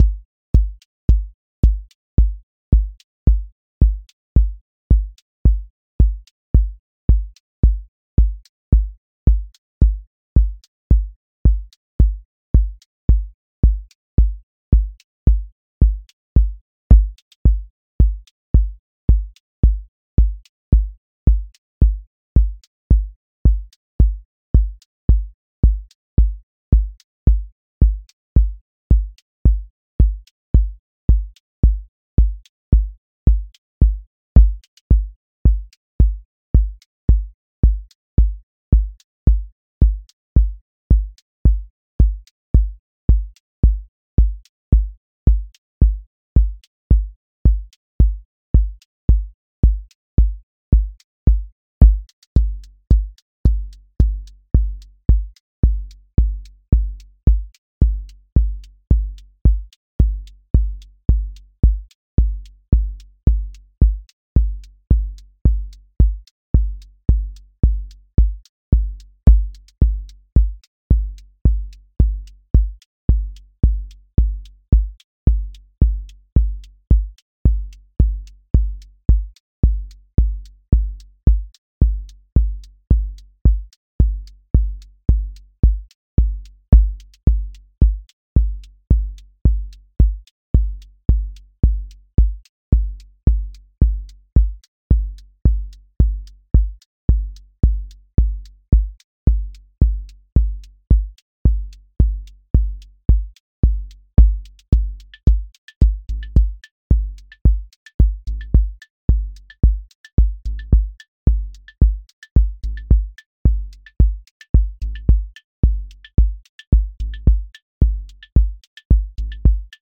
QA Listening Test trance Template: four_on_floor
• macro_house_four_on_floor
• voice_kick_808
• voice_hat_rimshot
• voice_sub_pulse